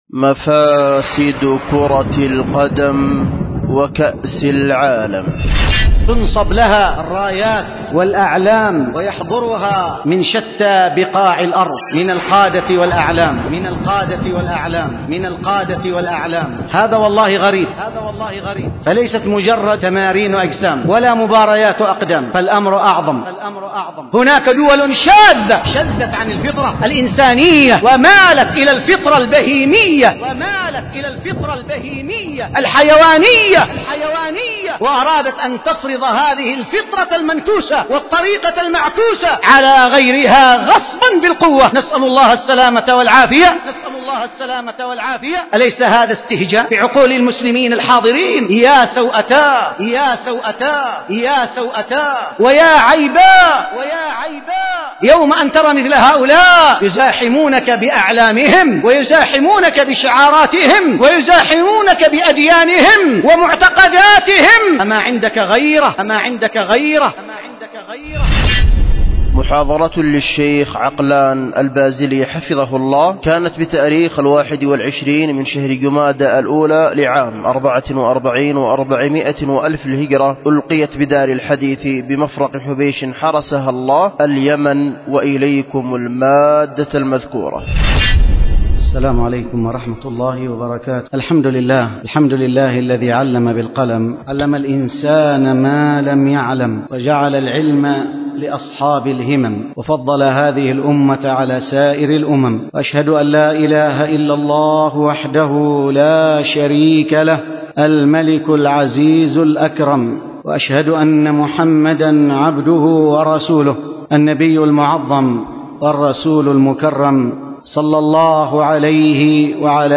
ألقيت بدار الحديث بمفرق حبيش